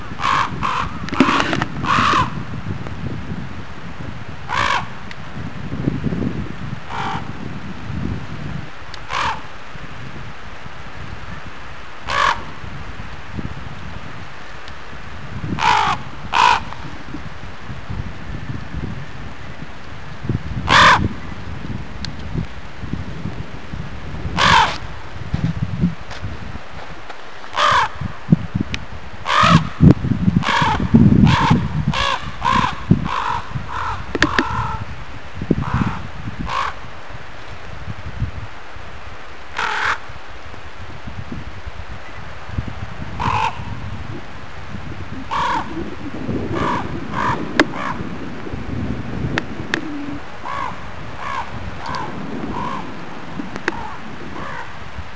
遠くで動物のけたたましい鳴き声が聞こえる。
朝食の時間に、早朝のけたたましい泣き声が話題なる。
ワタリガラスの大騒ぎだったようだ。
raven.wav